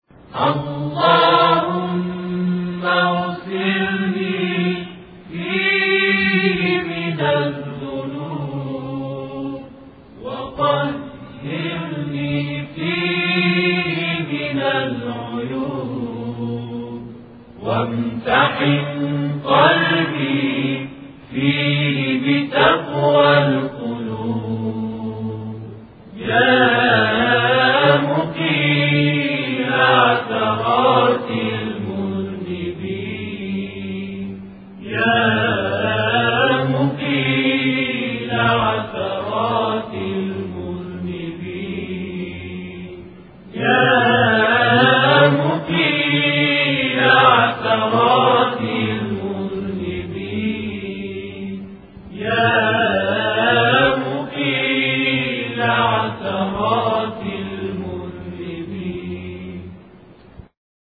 همخوانی دعای روز بیست و سوم ماه مبارک رمضان + متن و ترجمه
در این محتوا، متن کامل دعای روز بیست و سوم ماه مبارک رمضان به همراه ترجمه روان فارسی و صوت همخوانی ادعیه با صدایی آرامش‌بخش را دریافت کنید.
Tavashih-doa23-Ramazan.mp3